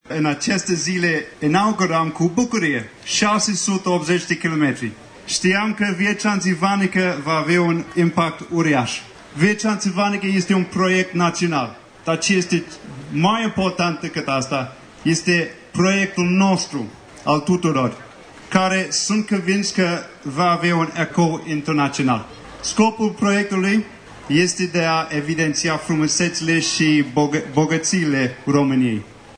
La momentul inaugural s-a aflat și Principele Nicolae al României, care este voluntar la Asociația Tășuleasa, cea care se ocupă de implementarea proiectului. Principele este convins că acest proiect va avea un ecou internațional: